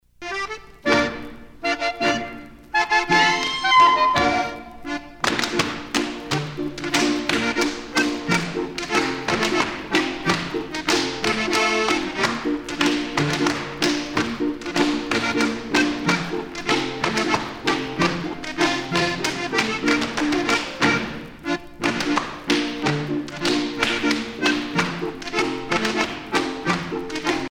danse : schuhplattler (Bavière)
Pièce musicale éditée